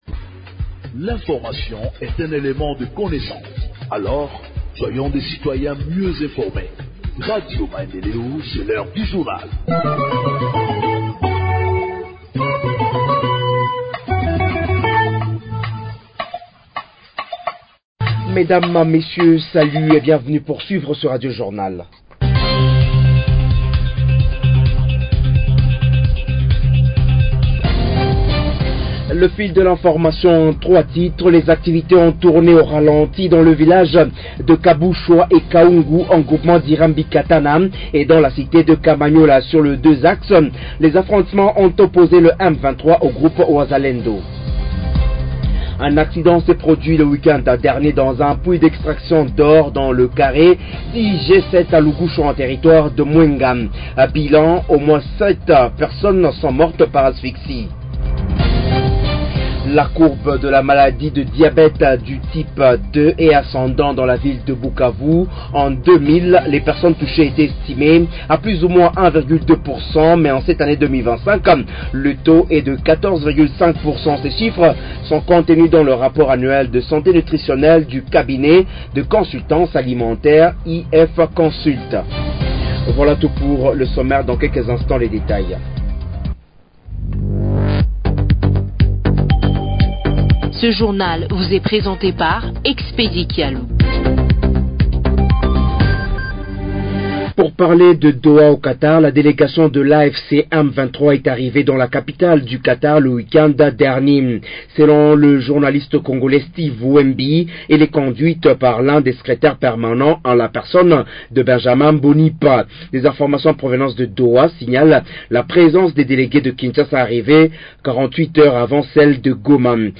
Journal en Français du 06 Mai025 – Radio Maendeleo